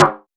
SNARE.55.NEPT.wav